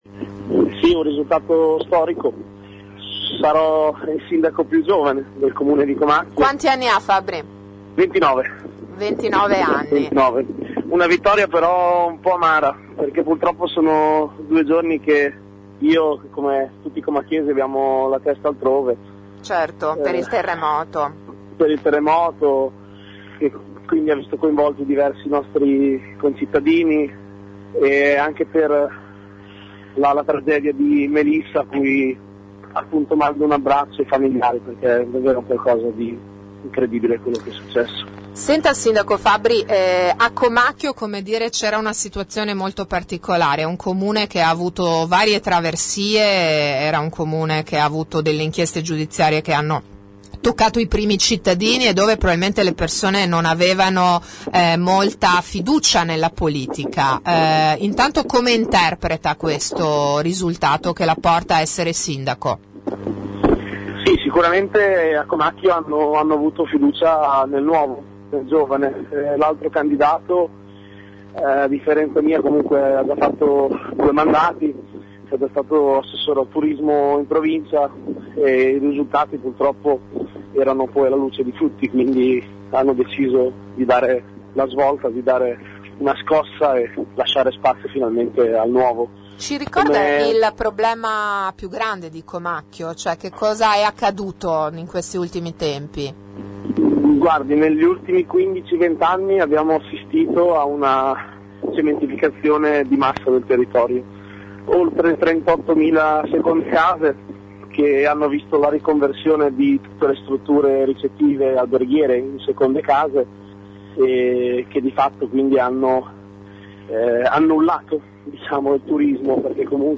Ascolta il neo sindaco di Comacchio